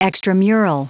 Prononciation du mot extramural en anglais (fichier audio)
Prononciation du mot : extramural